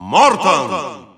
Announcer pronouncing Morton in Russian.
Morton_Russian_Announcer_SSBU.wav